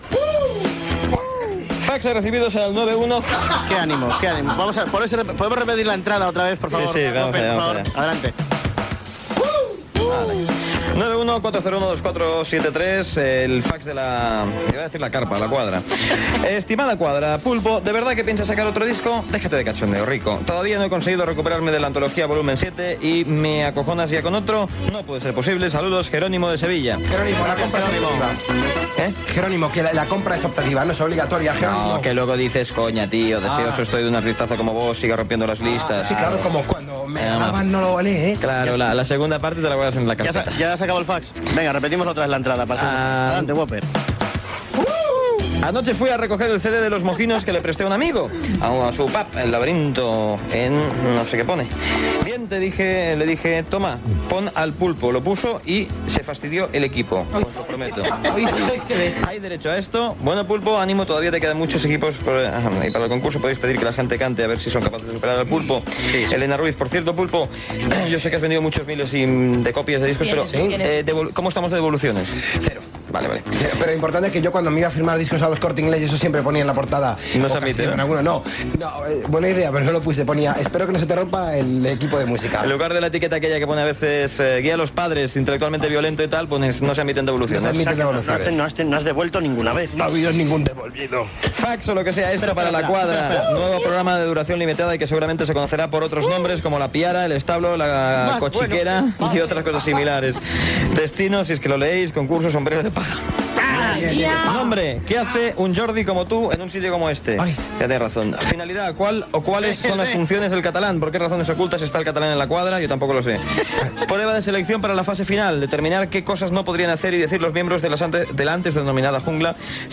Telèfon de contacte, lectura de missatges de l'audiència, indicatiu de l'emissora
Entreteniment
Gravació realitzada a València.